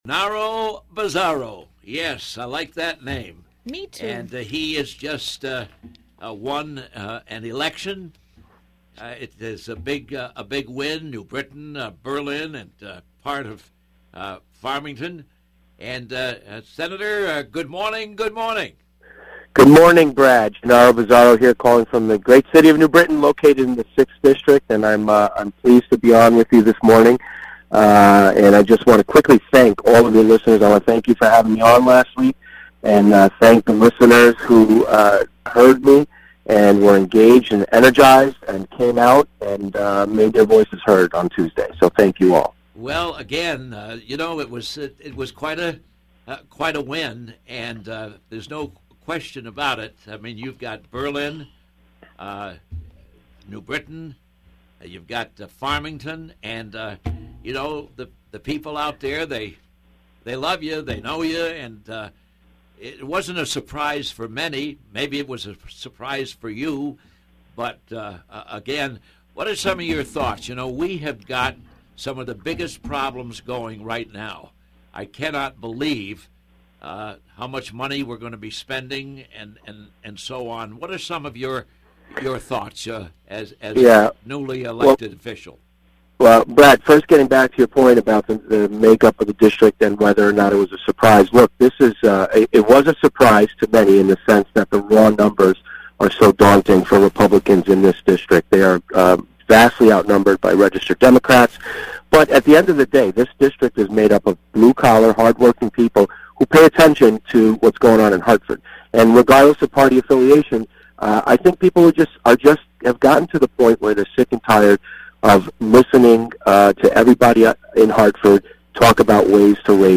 GOP State Senator-Elect Genarro Bizzarro, Attorney Bizzarro won a special election Tuesday and will represent New Britain, Berlin and part of Farmington, he called in to talk